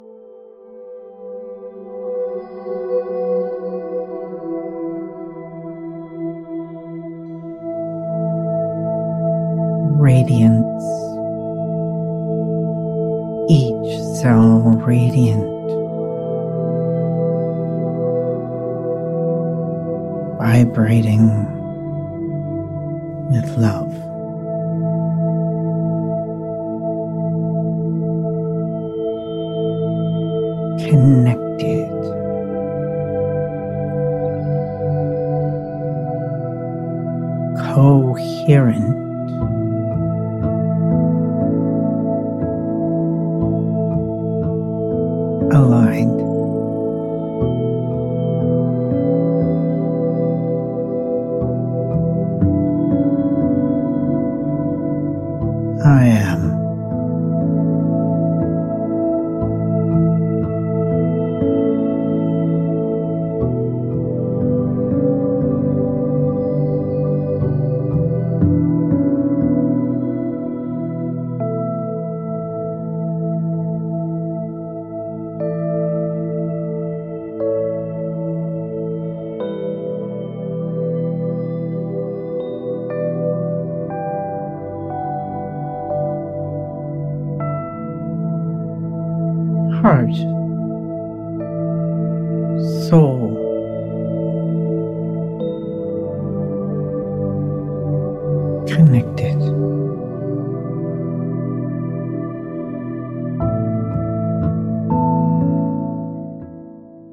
Guided Meditation 1